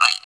PRC GUIRO 1.wav